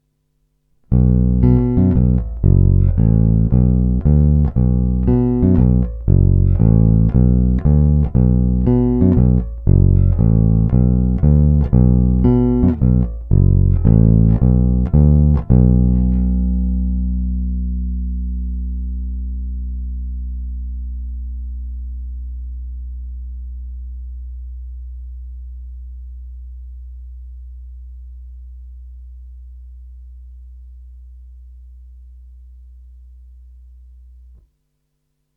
• Typ nástroje Jazz Bass
Basa má pevný, tvrdý, průrazný zvuk. Je to agresívní až uječená bestie, použití tónové clony je zde zcela namístě. Zvuk je hodně čistý, konkrétní vrčák, holt se zde projevuje hodně ten jasan.
Následují obligátní ukázky nahrané rovnou do zvukovky, jen normalizované, jinak ponechané bez dalších úprav.
Použité jsou nové niklové struny D'Addario EXL 170 v tloušťkách .045" až 100".
Snímač u krku (hráno nad tímto snímačem)